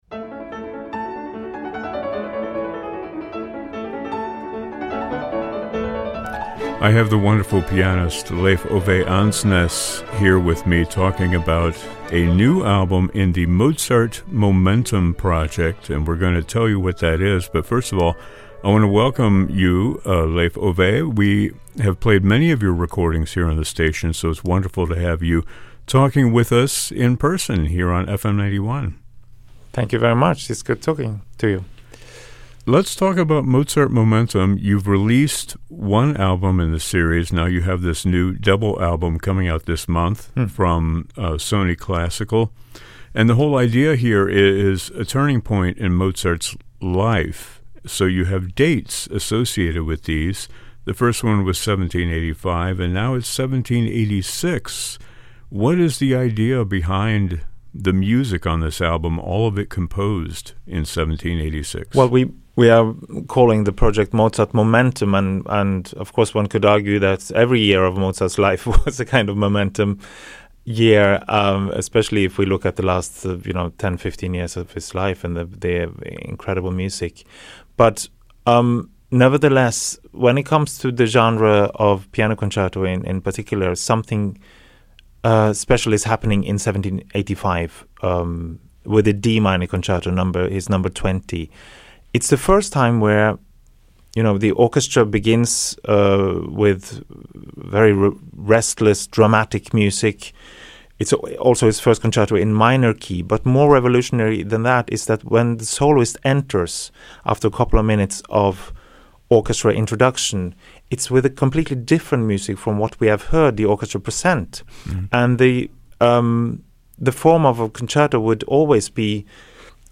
The acclaimed Norwegian pianist Leif Ove Andsnes joins us to talk about the second album release in his Mozart Momentum project, which documents the genius of Wolfgang Mozart by focusing on touchstone years in the composer's life (in this case, 1786).